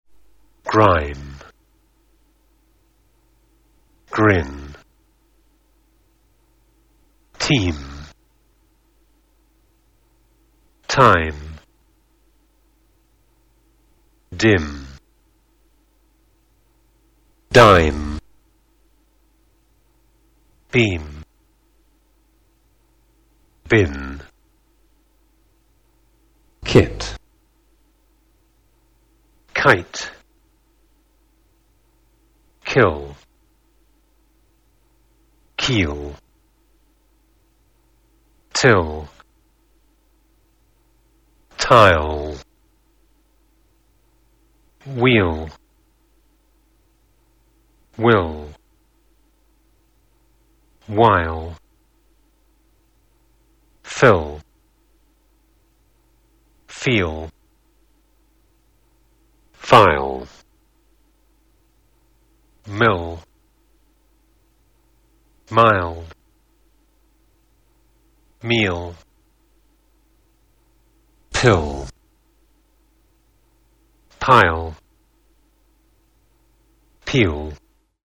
i_long_short_diphthong.mp3